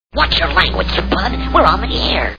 Looney Toons TV Show Sound Bites